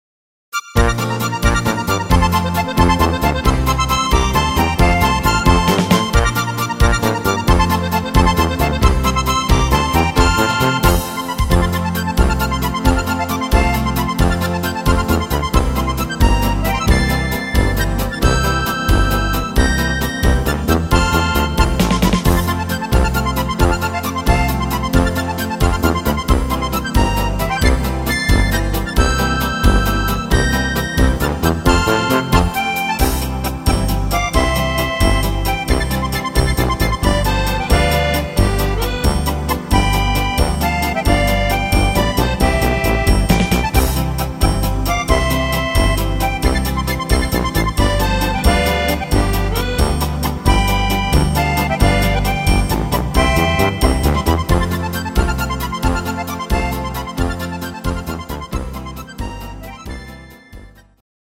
instr. steirische Harmonika